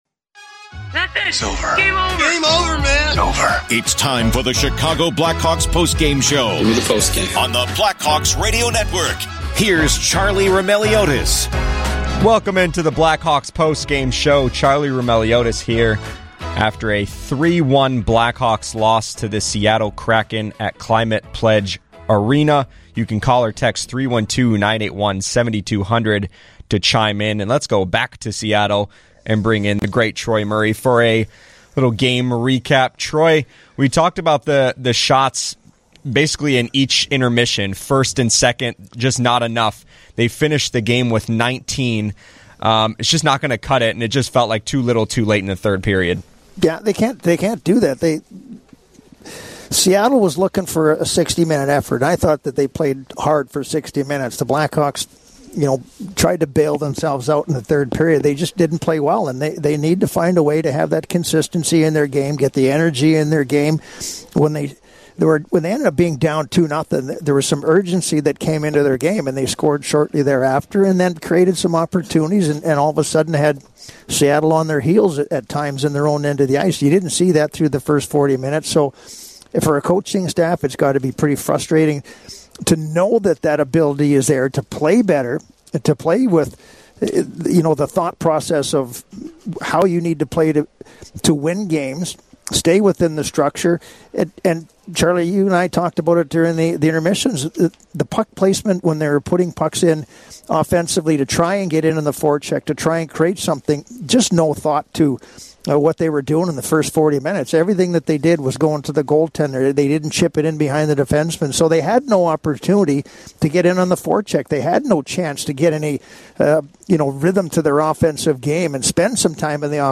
Troy Murray joins the discussion from Climate Pledge Arena to discuss why the Blackhawks are having trouble scoring, how they can be better with dump-in attempts, and more.
Later in the show, Ryan Donato, Nick Foligno and head coach Luke Richardson share their thoughts on the loss.